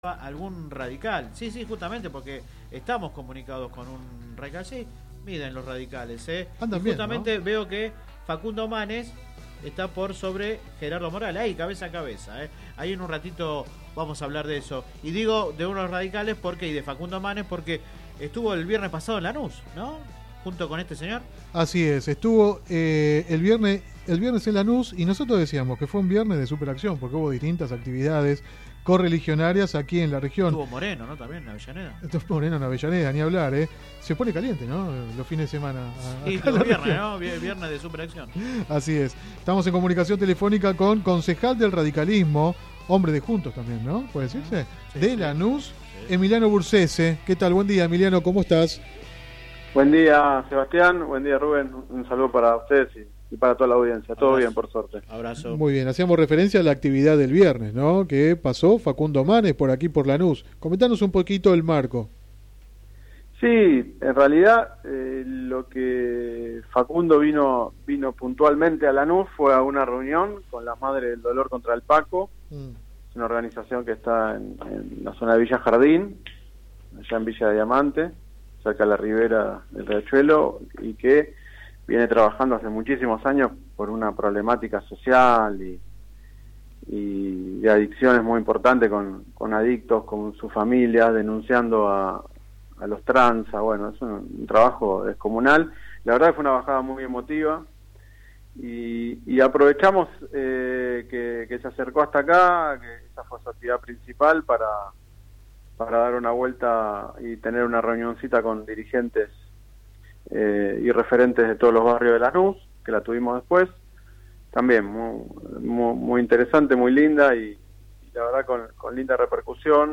El concejal y precandidato a intendente radical de Lanús, Emiliano Bursese, habló sobre la reciente visita de Facundo Manes al municipio, la situación de la UCR y la relación con el PRO en el distrito. El edil radical habló en el programa radial Sin Retorno (lunes a viernes de 10 a 13 por GPS El Camino FM 90 .7 y AM 1260). Durante la entrevista brindó un panorama sobre la interna de su partido.